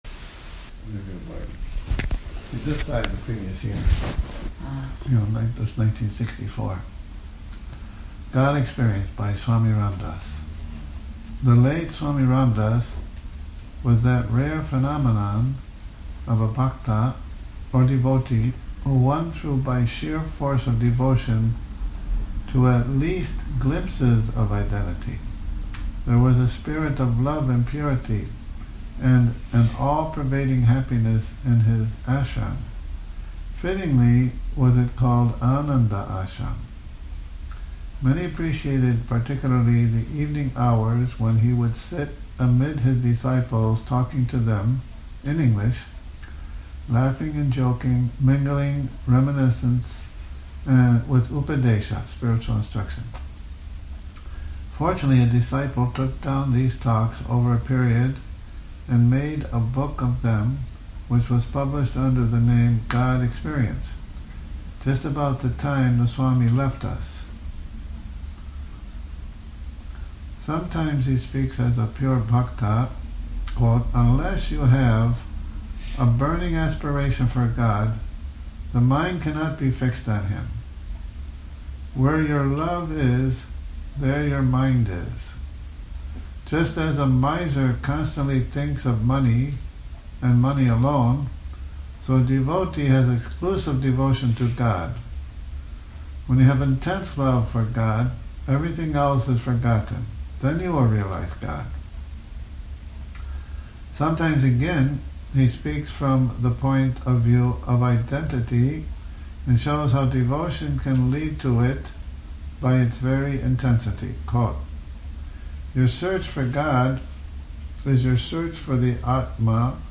Morning Reading, 07 Oct 2019